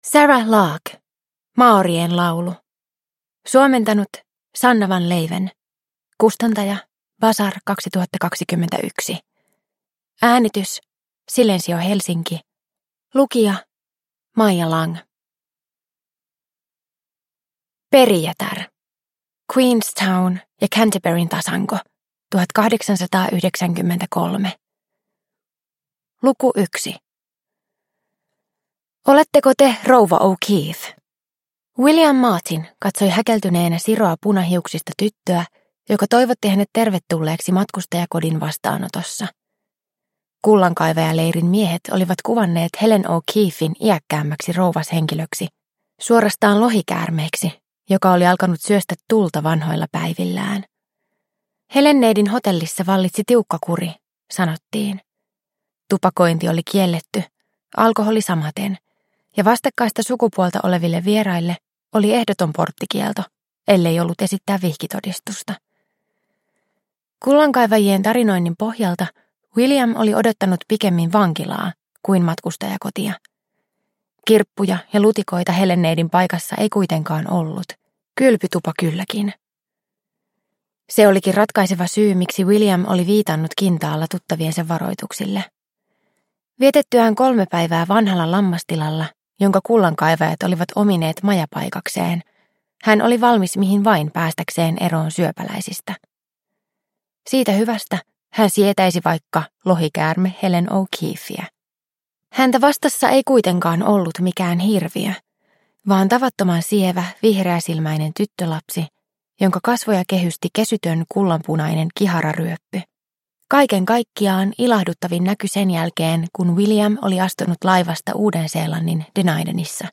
Maorien laulu – Ljudbok – Laddas ner